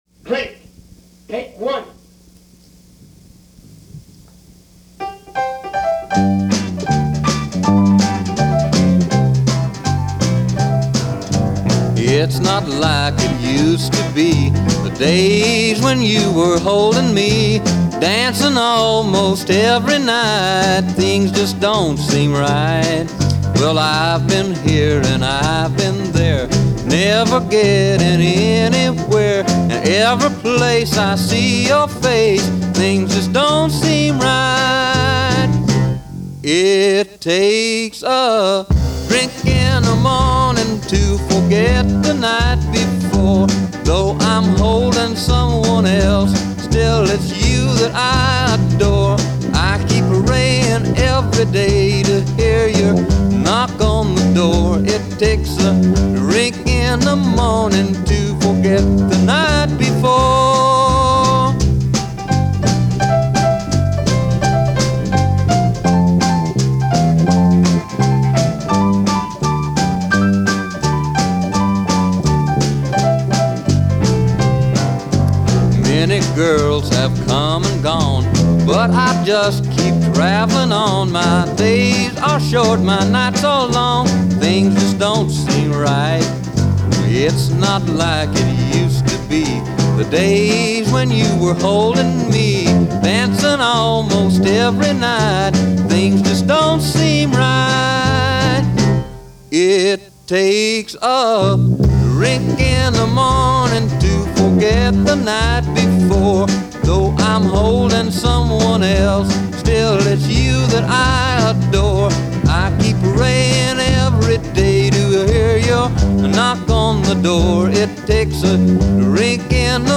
Rockabilly
piano
” with two takes, the second being the “circled” take.